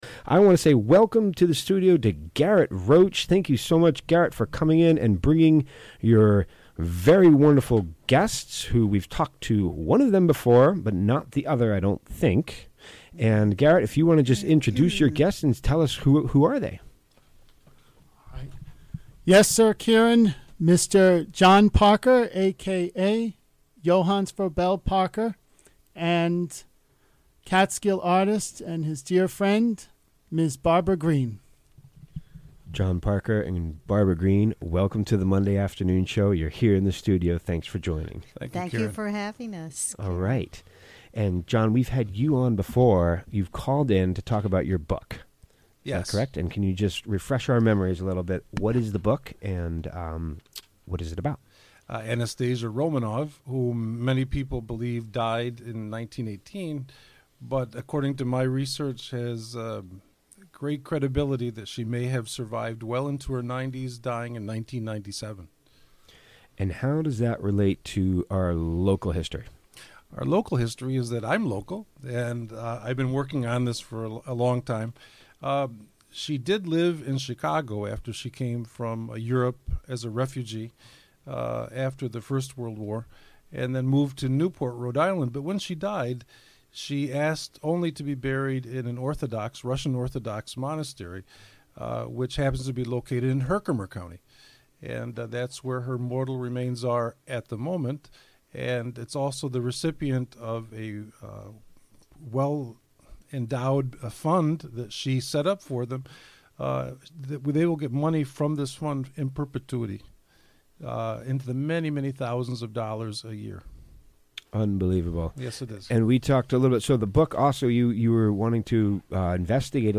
Recorded live on the WGXC Afternoon show on May 7, 2018.